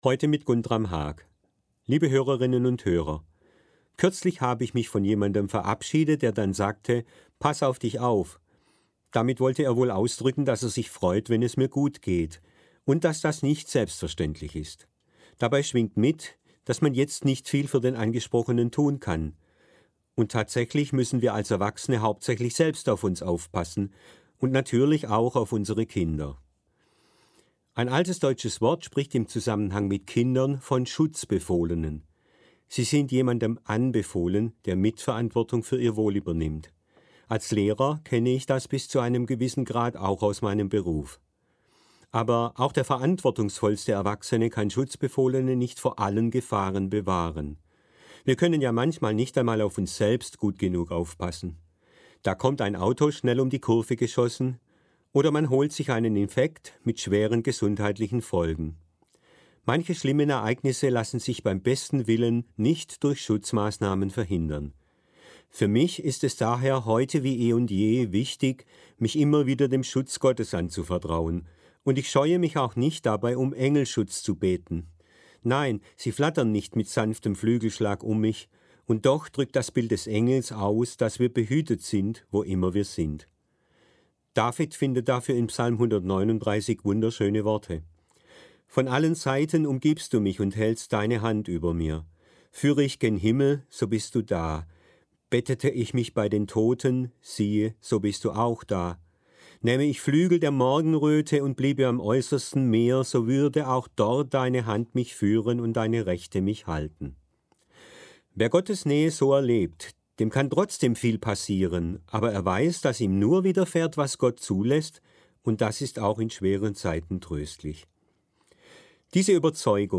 An jedem vierten Sonntag im Monat verantwortet die Gebietskirche Süddeutschland eine Sendung im Hörfunkprogramm des Südwestrundfunks: Sie wird im Magazin aus Religion, Kirche und Gesellschaft „SWR1 Sonntagmorgen“ ausgestrahlt, jeweils um 7:27 Uhr (Verkündigungssendung mit 2,5 Minuten Sendezeit).